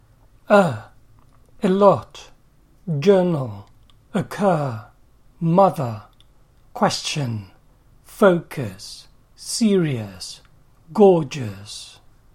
ə
a lot, journal, occur, mother, question, focus, serious, gorgeous
ə.mp3